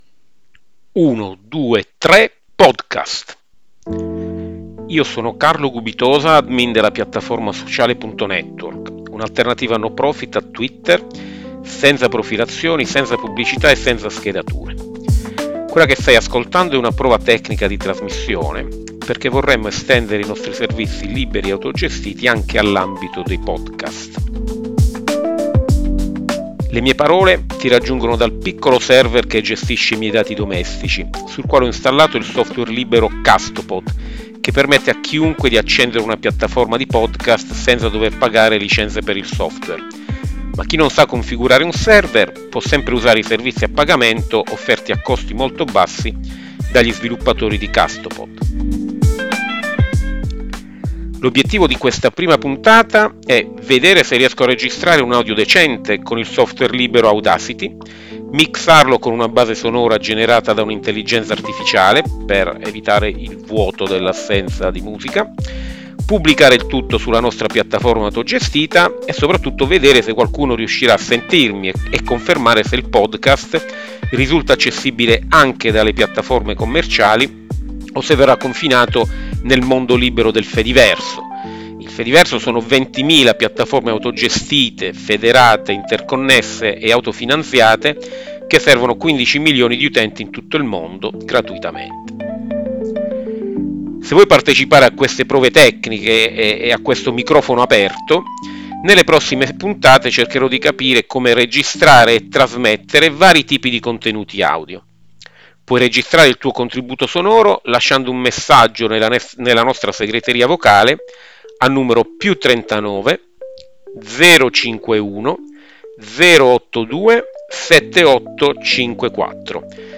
L’obiettivo di questa prima puntata è vedere se riesco a registrare un audio decente con il software libero audacity, mixarlo con una base sonora generata da una intelligenza artificiale, pubblicarlo sulla nostra piattaforma autogestita, vedere se qualcuno riuscirà a sentirmi e confermare se il podcast risulta accessibile dalle piattaforme commerci…